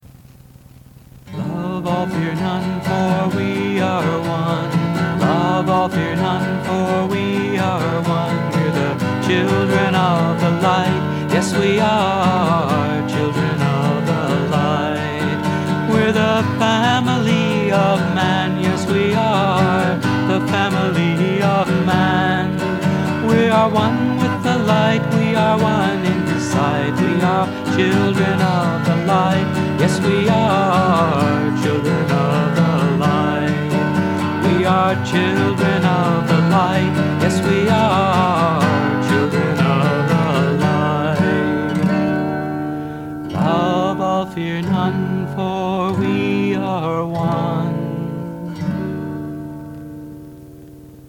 1. Devotional Songs
Major (Shankarabharanam / Bilawal)
8 Beat / Keherwa / Adi
4 Pancham / F
1 Pancham / C